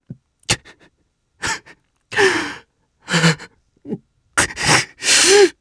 Roman-Vox_Sad_jp.wav